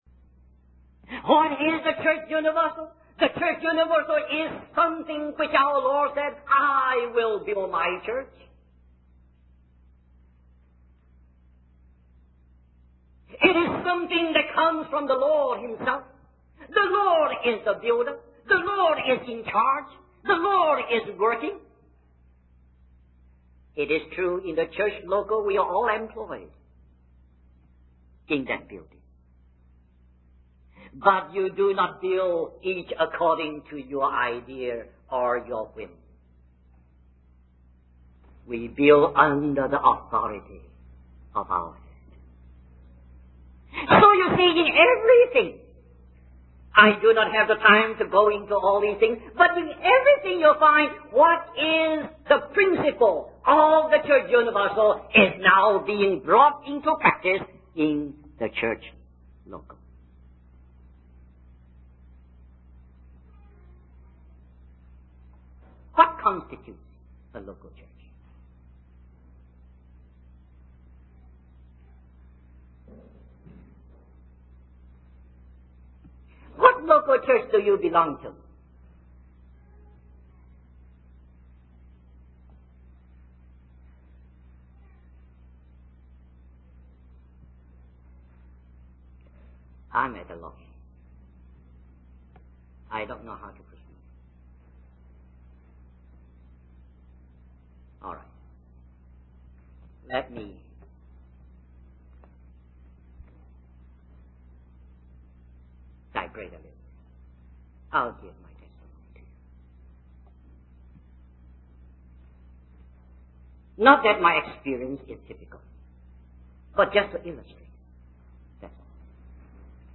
In this sermon, the speaker reflects on his journey in serving the Lord and the struggles he faced. He emphasizes the importance of returning to simplicity and unity in the church. The speaker recounts a pivotal moment when he heard a message on counting the cost before following the Lord.